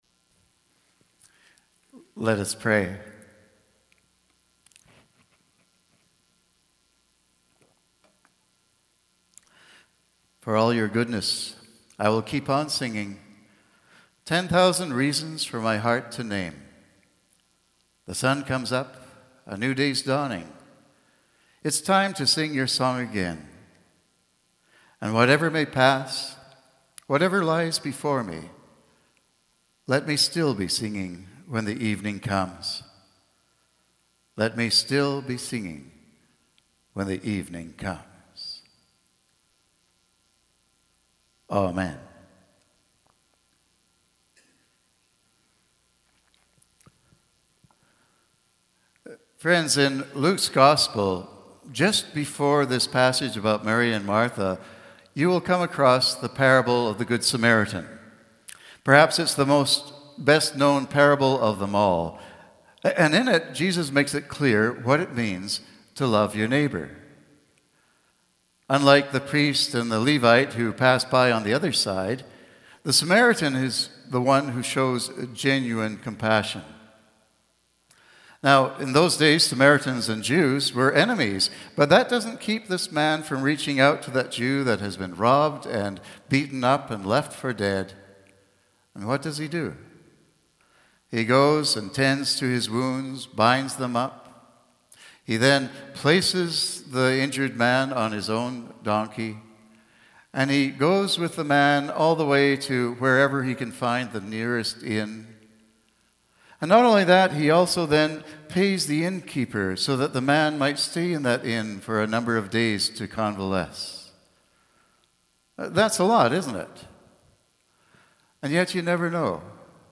Sermons | Knox United Church